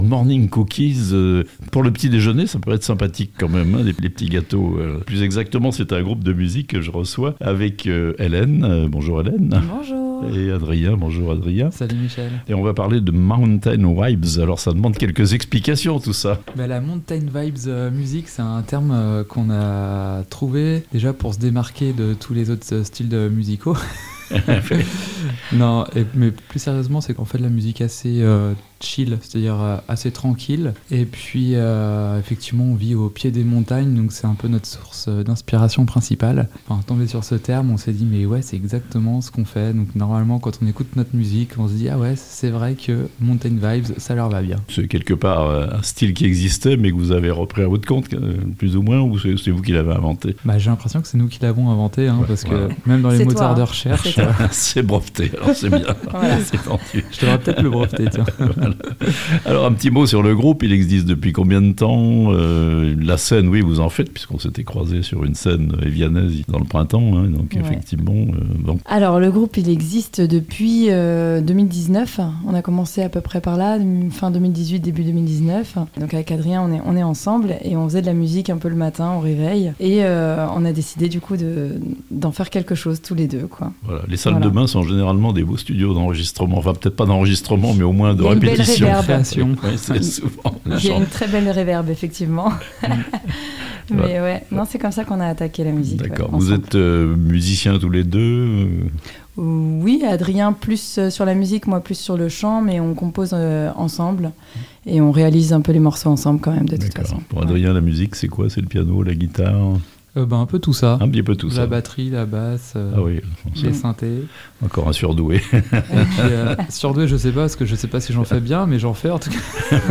Morning Cookies, les montagnes inspirent leur musique ! (interviews)